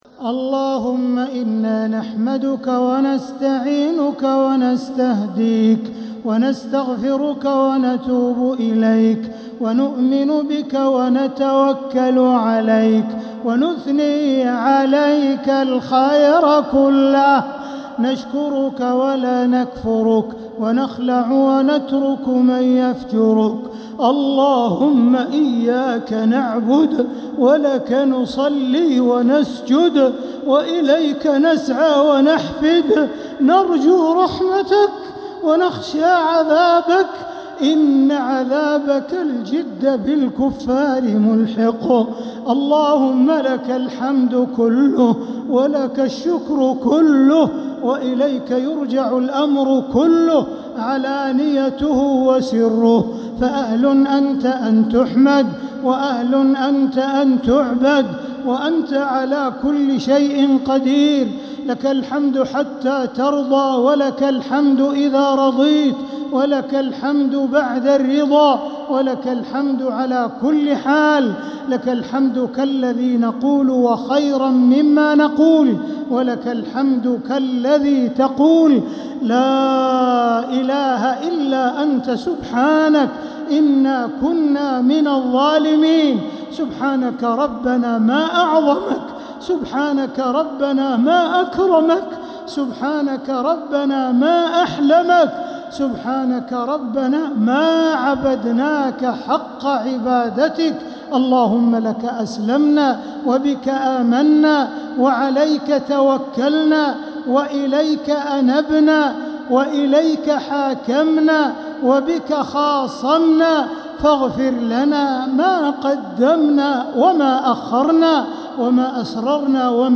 دعاء القنوت ليلة 7 رمضان 1447هـ | Dua 7th night Ramadan 1447H > تراويح الحرم المكي عام 1447 🕋 > التراويح - تلاوات الحرمين